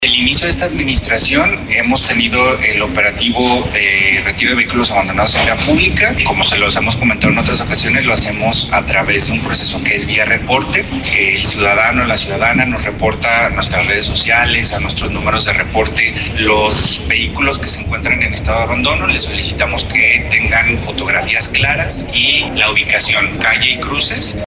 Fueron retirados… El municipio de Guadalajara dio a conocer que a través de la dirección de movilidad retiraron 700 vehículos abandonados de la vía pública 220 fueron trasladados al corralón debido a que los dueños no atendieron las notificaciones, habla el director de movilidad de Guadalajara Saúl González.